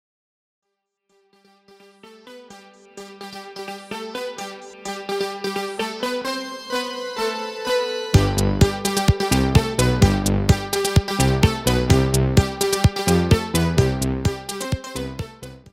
165 - MUSIQUE DE SERIE